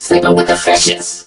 mech_crow_kills_02.ogg